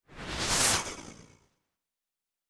pgs/Assets/Audio/Sci-Fi Sounds/Movement/Synth Whoosh 4_1.wav at master
Synth Whoosh 4_1.wav